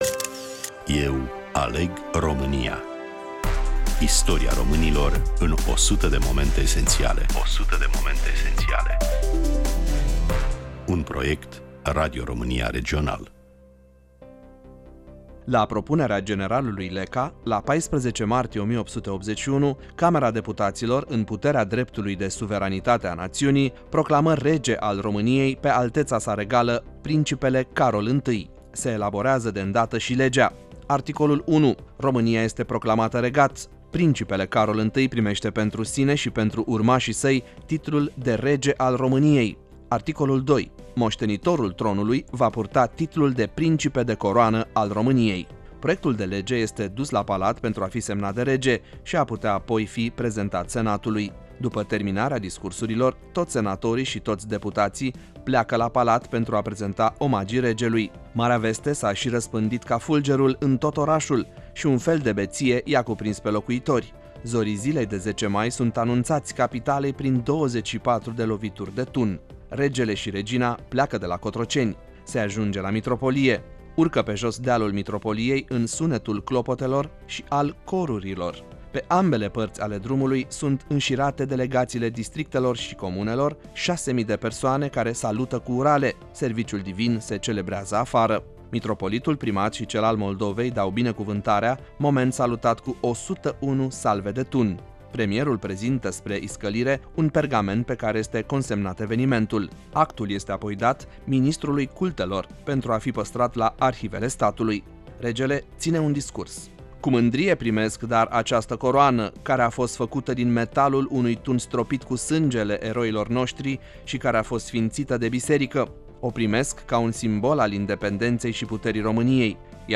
Prezentare, voice over